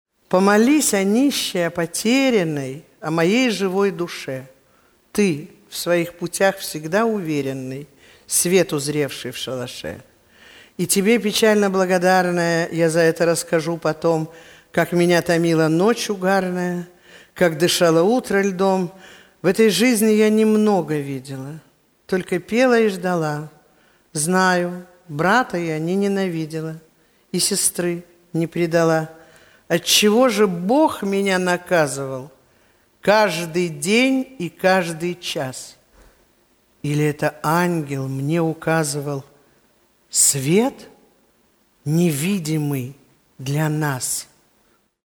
Помолись о нищей, о потерянной...Исполняет: Крючкова Светлана